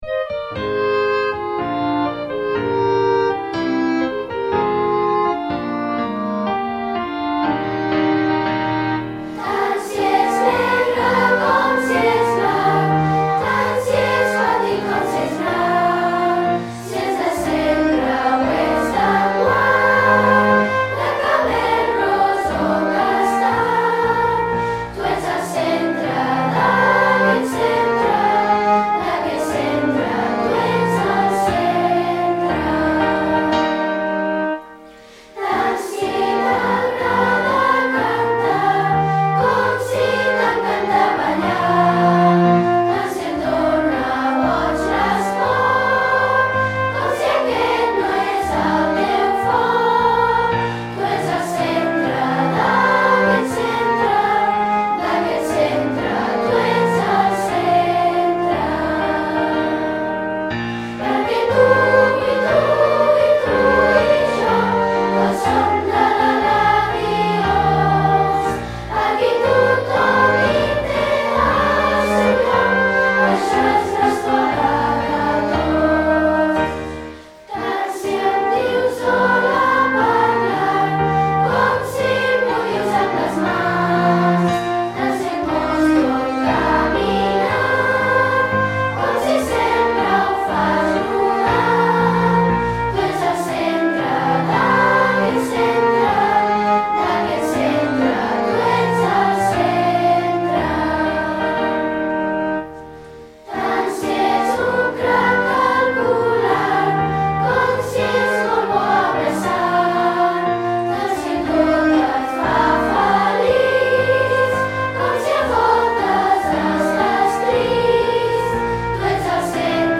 Us donem la benvinguda a l’escola pública Eladi Homs de Valls tot cantant el nostre himne